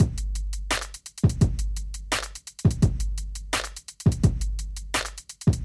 Willst du z.B. 1/8-Noten und 1/8-Triolen in einem Pattern haben, musst du eben 1/16-Triolen als Raster nehmen. Zum Beispiel so (mal schnell über's Notebook, bin im Urlaub): Und klingt so: